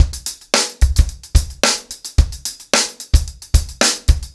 TupidCow-110BPM.37.wav